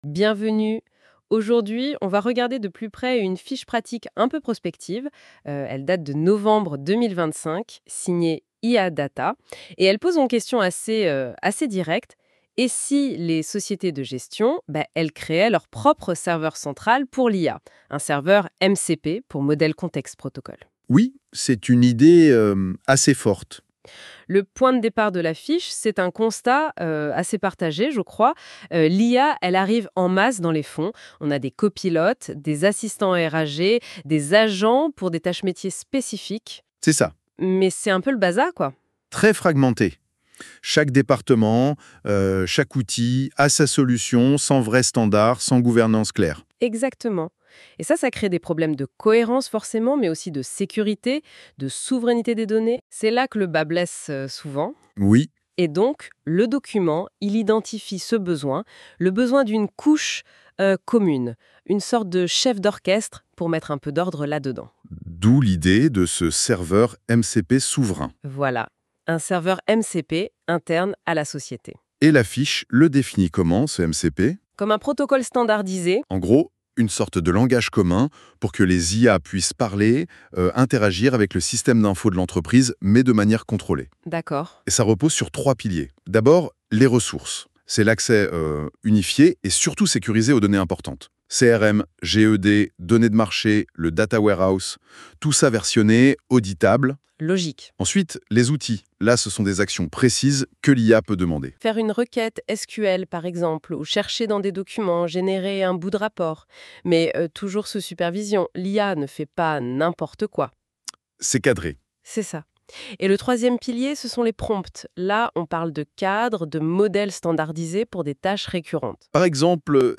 NB : le podcast de BODIC est généré par l’outil d’IA NotebookLM à partir du contenu de cette fiche PDF écrite par l’équipe Bodic CONSTAT : L’IA PROGRESSE…